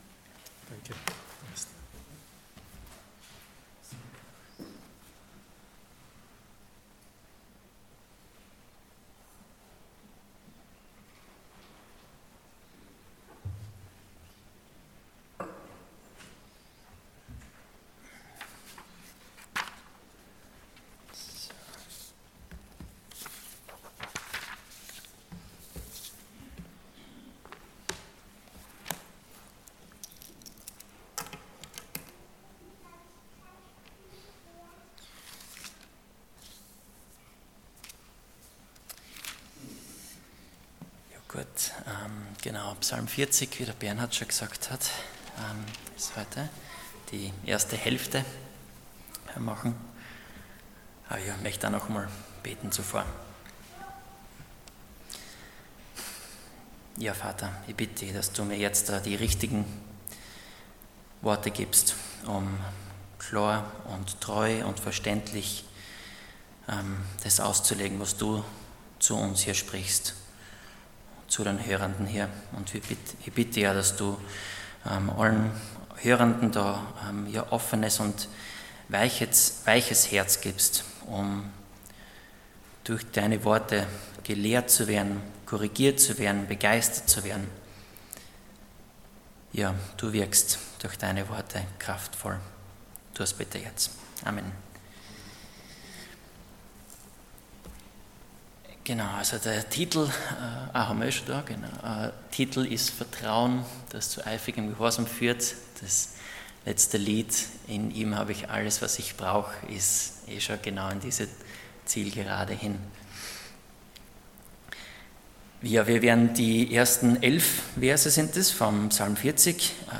Allgemeine Predigten Passage: Psalm 40:1-11 Dienstart: Sonntag Morgen